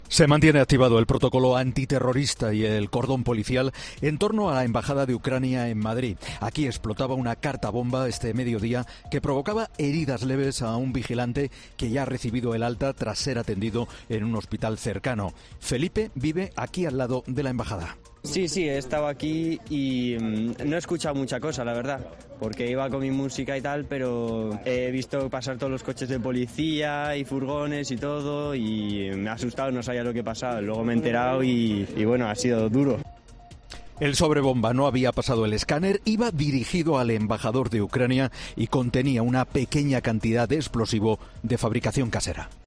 desde la unidad móvil de COPE en la Embajada de Ucrania